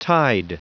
Prononciation du mot tide en anglais (fichier audio)
Prononciation du mot : tide